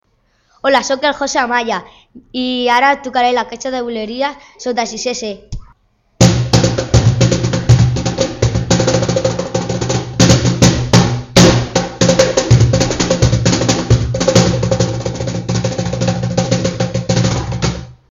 Bulerias